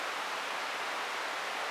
soft-sliderslide.ogg